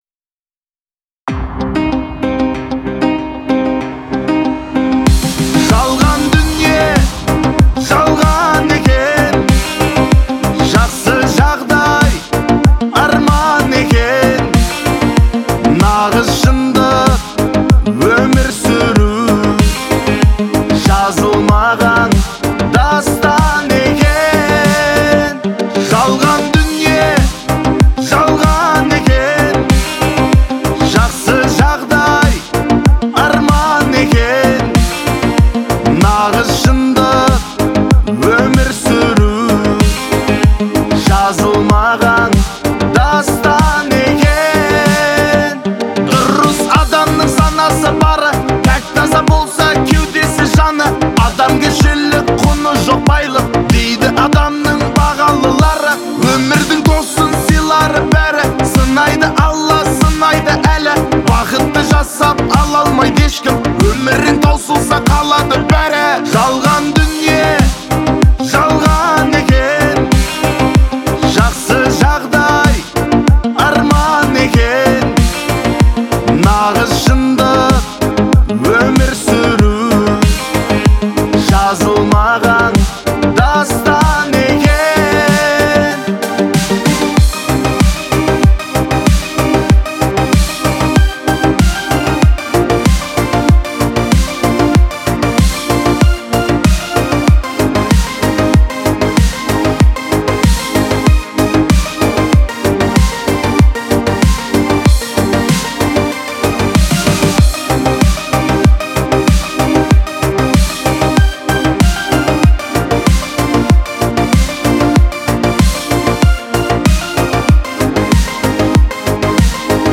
• Жанр: Казахские песни